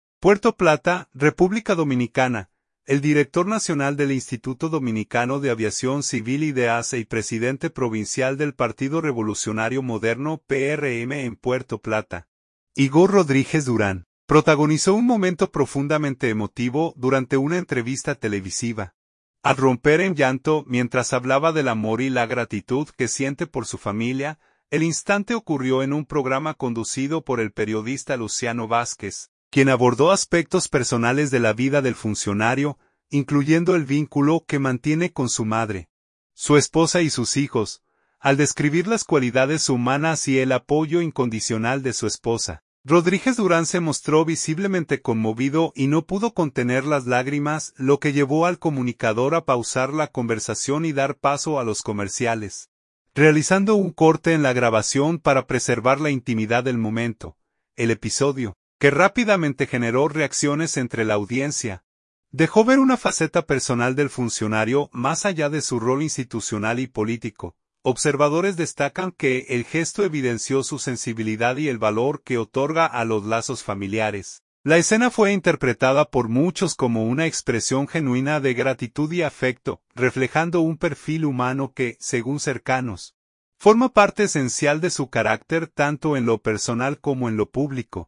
Igor Rodríguez Durán se emociona hasta las lágrimas al hablar de su familia en plena entrevista
Puerto Plata, República Dominicana. – El director nacional del Instituto Dominicano de Aviación Civil (IDAC) y presidente provincial del Partido Revolucionario Moderno (PRM) en Puerto Plata, Igor Rodríguez Durán, protagonizó un momento profundamente emotivo durante una entrevista televisiva, al romper en llanto mientras hablaba del amor y la gratitud que siente por su familia.
Al describir las cualidades humanas y el apoyo incondicional de su esposa, Rodríguez Durán se mostró visiblemente conmovido y no pudo contener las lágrimas, lo que llevó al comunicador a pausar la conversación y dar paso a los comerciales, realizando un corte en la grabación para preservar la intimidad del momento.